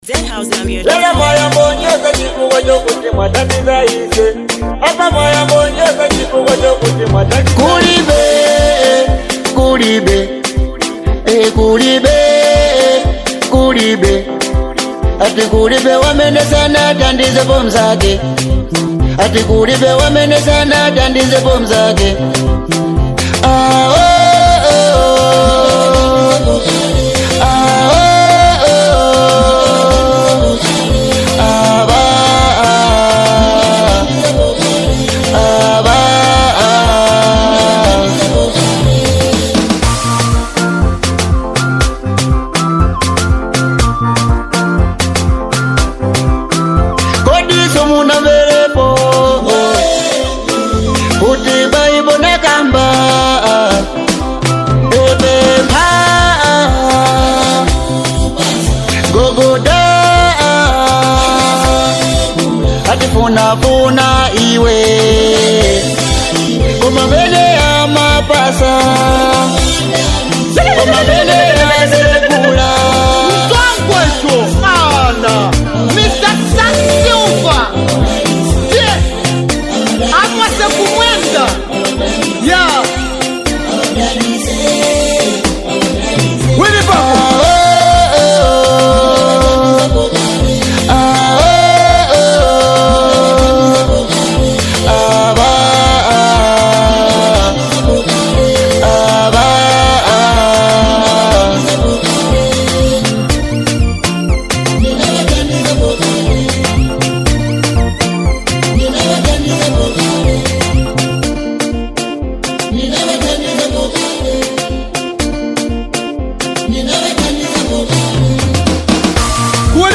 a faith-filled anthem